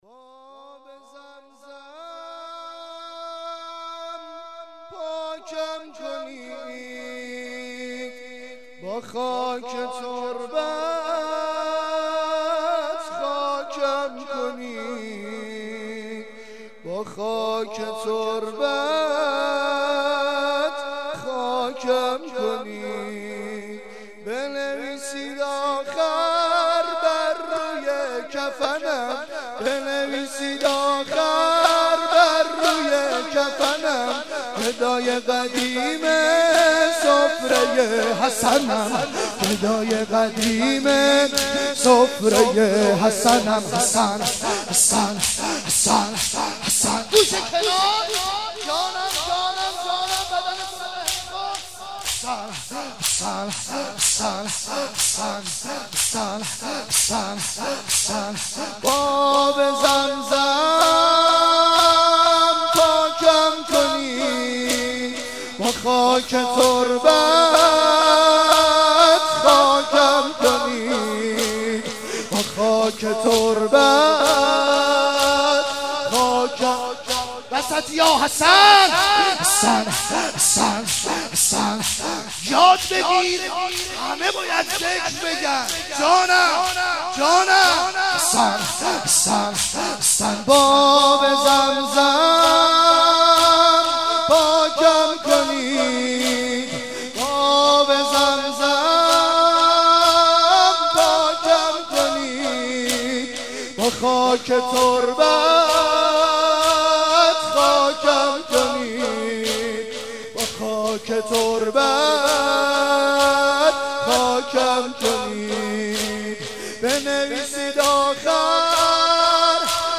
03.sineh zani.mp3